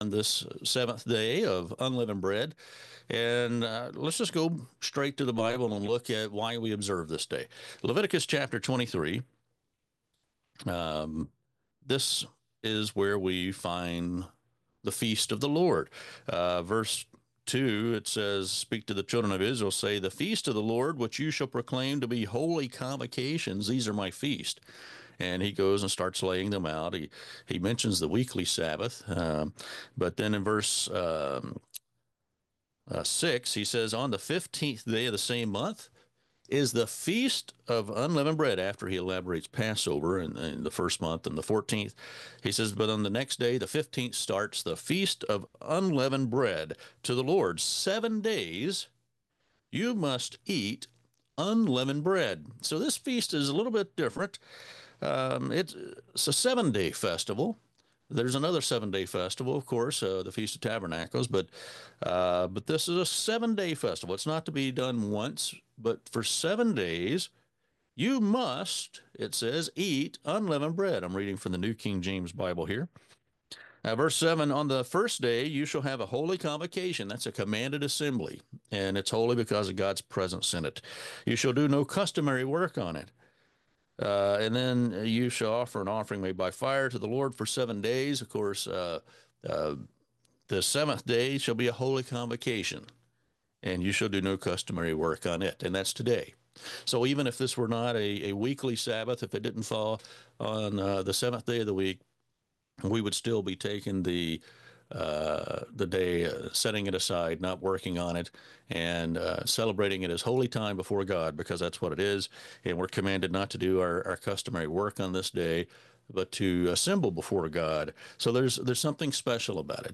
Last Day of Unleavened Bread service.
Service Type: Sermon